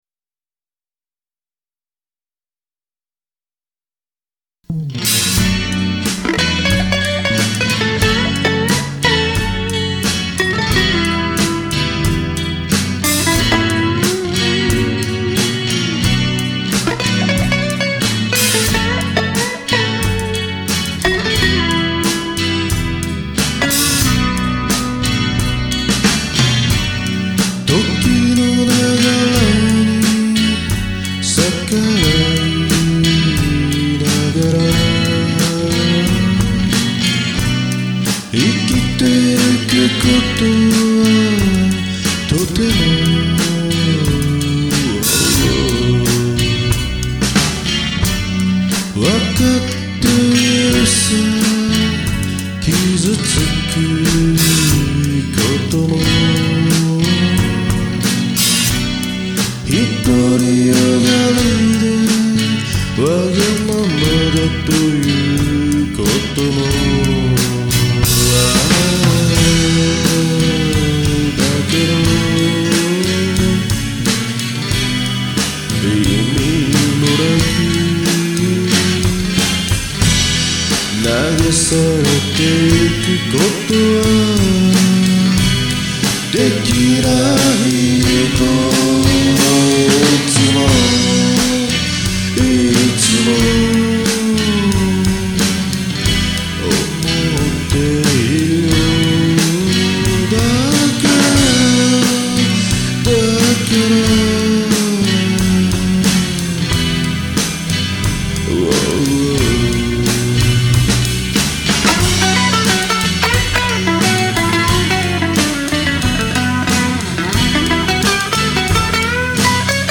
Guiter
Bass
Vocal